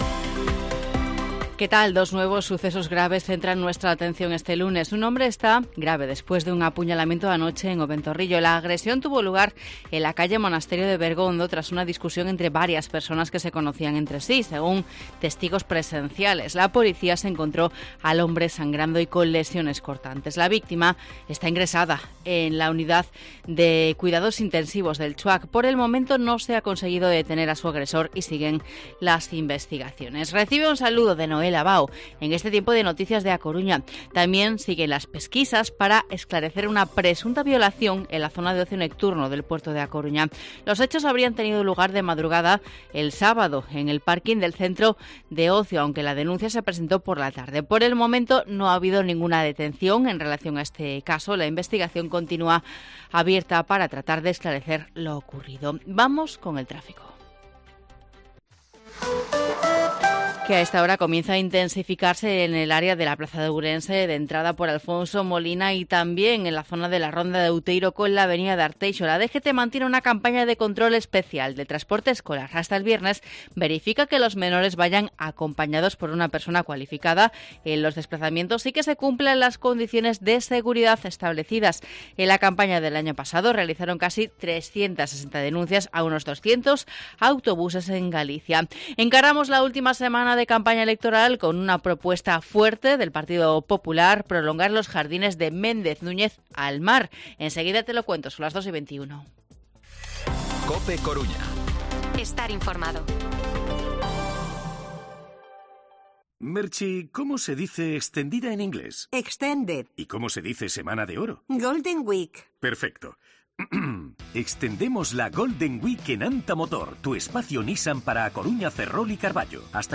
Informativo Mediodía COPE Coruña lunes, 22 de mayo de 2023 14:20-14:30